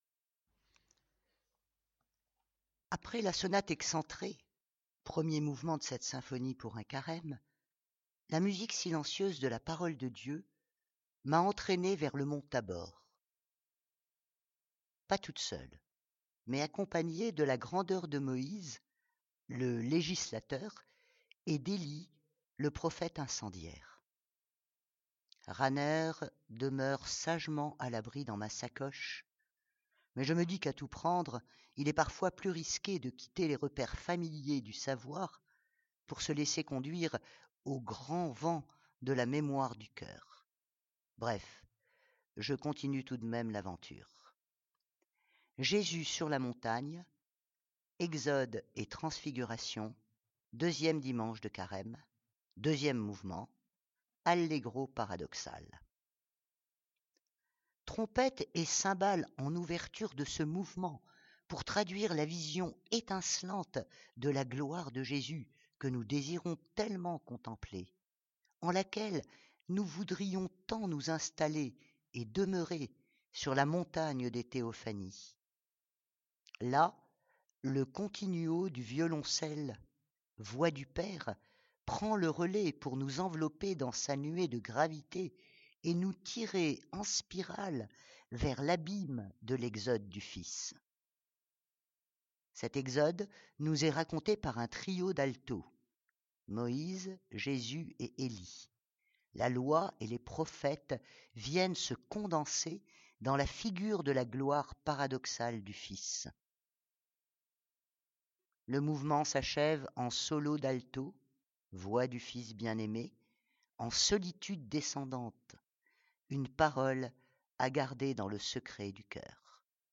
Trompettes et cymbales en ouverture de ce mouvement, pour traduire la vision étincelante de la gloire de Jésus que nous désirons tellement contempler, en laquelle nous voudrions tant nous installer, et demeurer, sur la montagne des théophanies…
Là, le continuo du violoncelle, voix du Père, prend le relais pour nous envelopper dans sa nuée de gravité et nous tirer, en spirale, vers l’abîme de l’exode du Fils.
Cet exode nous est raconté par un trio d’alto – Moïse, Jésus et Elie – la Loi et les Prophètes viennent se condenser dans la figure de la gloire paradoxale du Fils.
Le mouvement s’achève en solo d’alto – voix du Fils Bien-Aimé -, en solitude descendante : une parole à garder dans le secret du cœur.